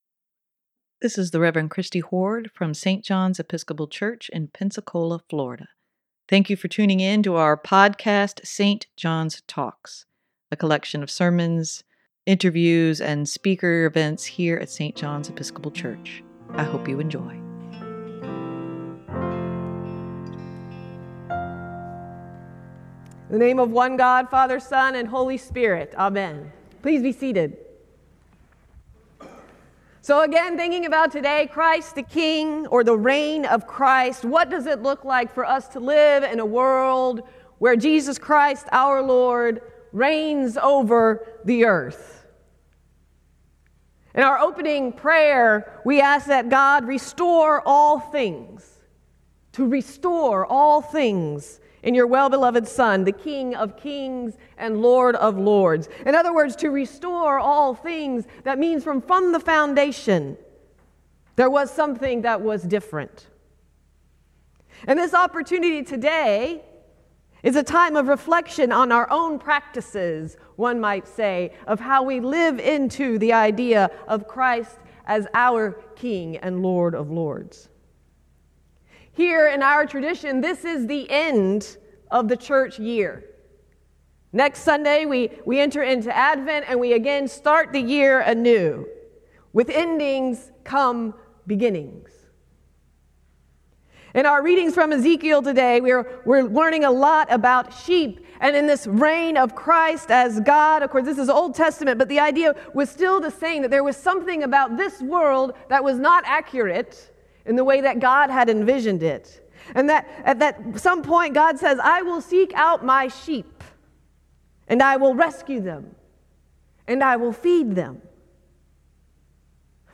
Sermon for Nov. 26, 2023: First, I am a Christian - St. John's Episcopal Church